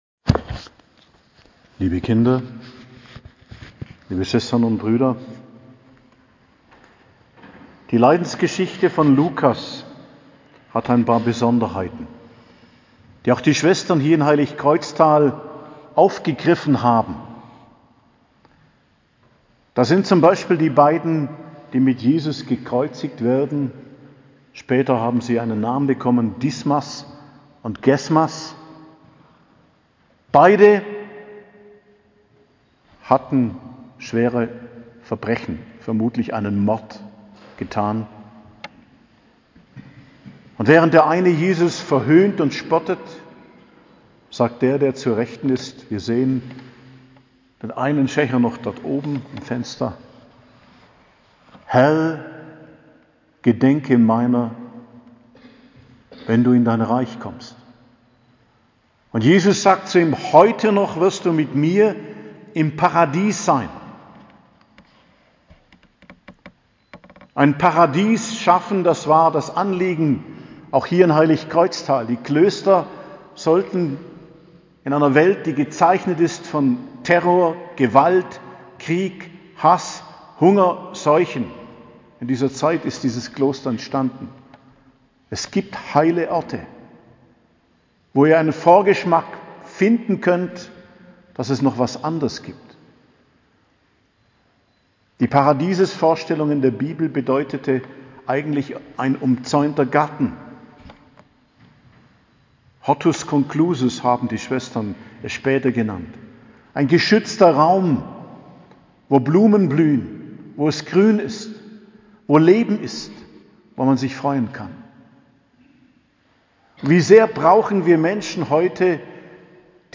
Predigt zum Palmsonntag am 10.04.2022